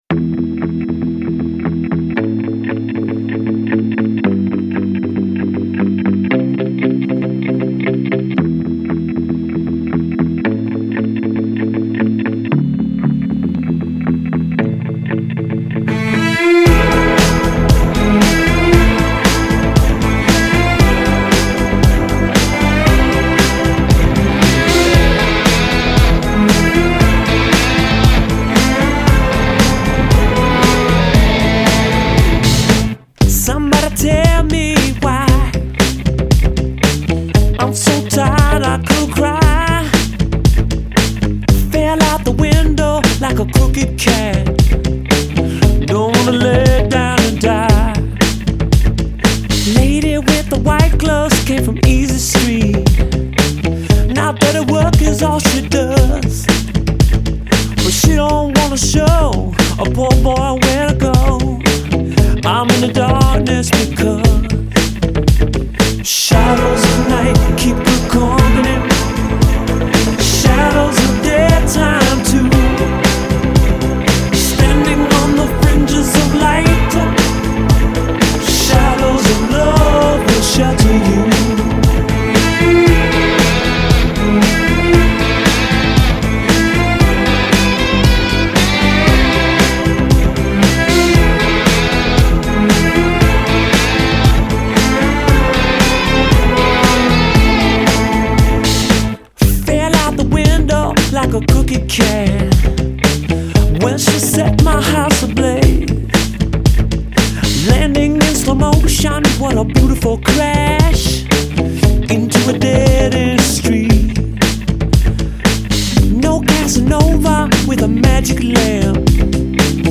drum programmer
keyboardist
something akin to the retrosoul